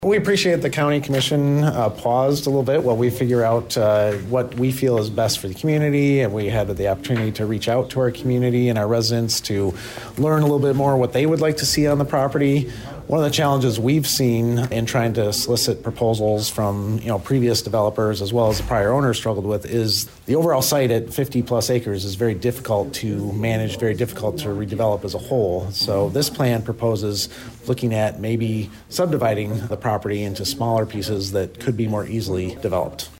Manager Swallow said that he appreciated the ‘pause’…